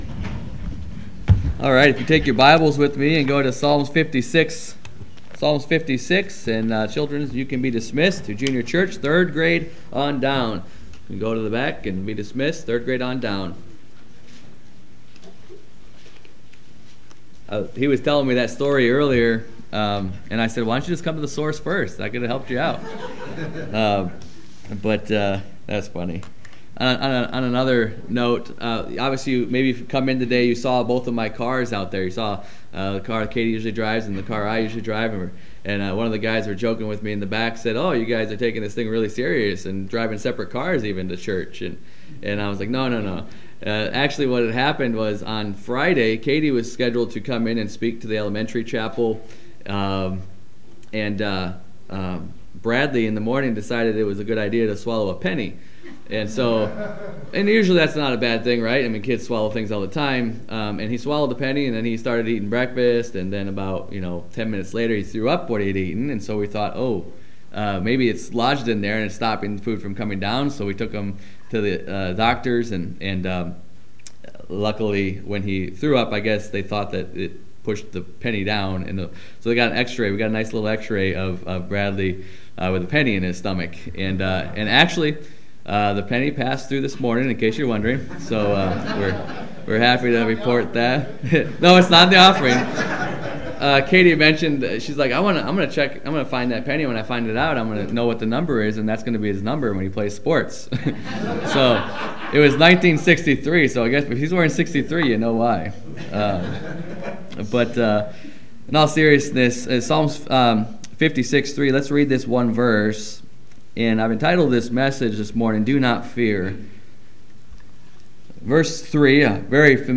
Sermons – Page 17 – Bible Baptist Church of Utica
Morning Service